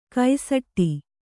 ♪ kaisaṭṭi